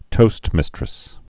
(tōstmĭstrĭs)